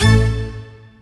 hitTTE68020stabhit-A.wav